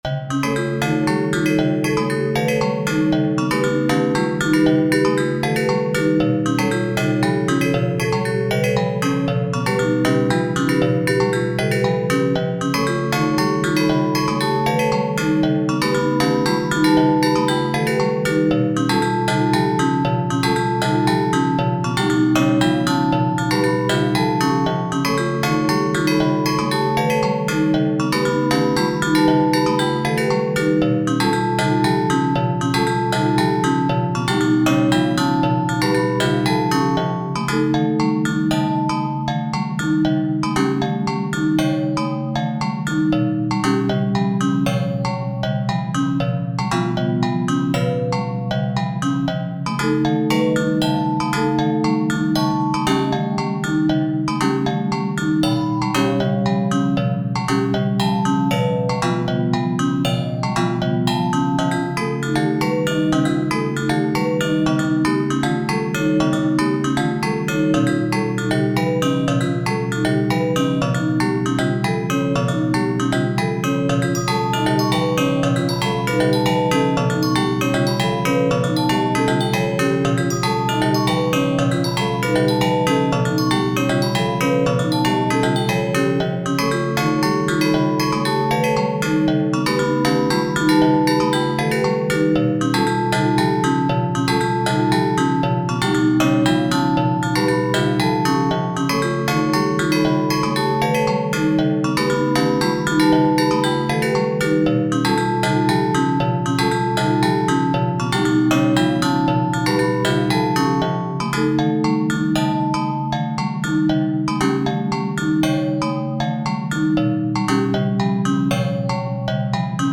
不思議で無機質な雰囲気のBGM。 ホールトーンスケールで作っており、6拍子と相まって独特な浮遊感が出ている。